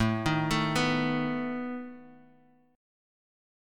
Asus2b5 Chord